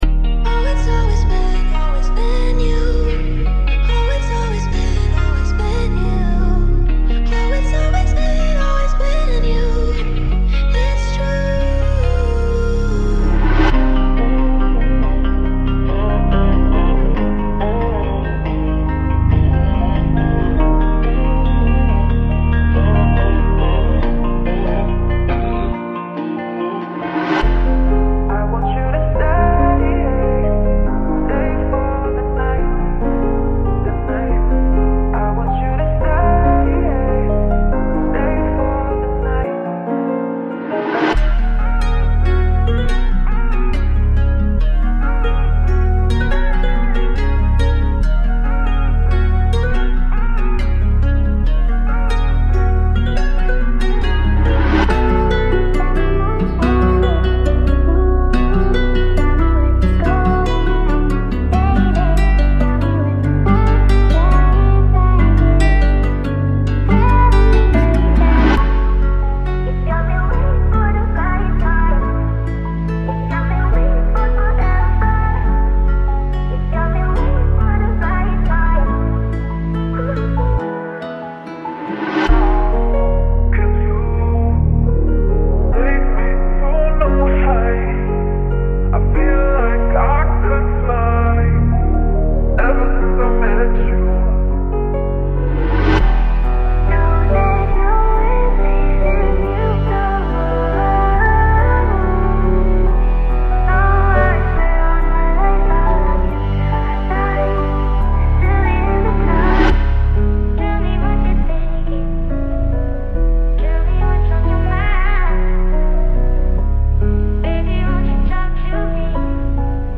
10 Melodic Drill Loops